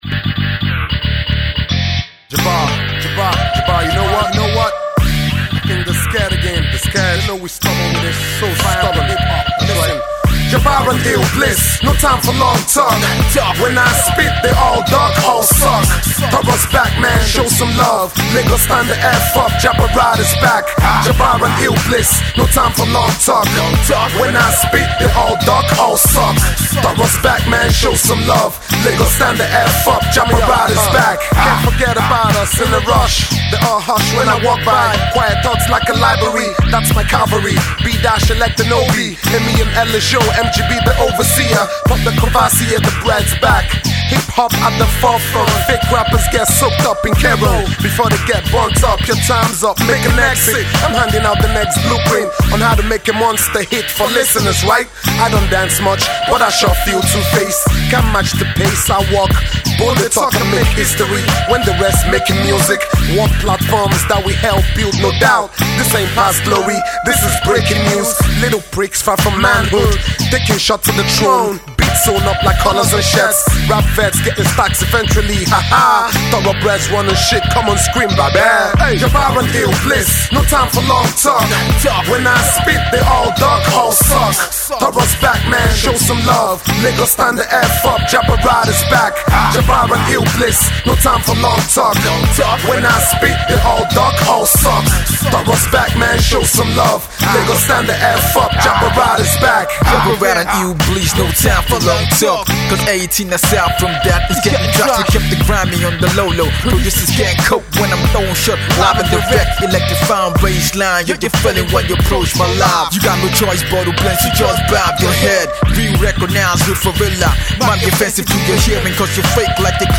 This is a tune for Hip-Hop heads.